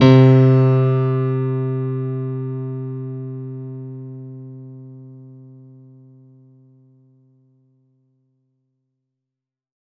R  C2  DANCE.wav